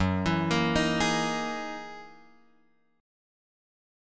F#13 chord {2 1 2 4 4 4} chord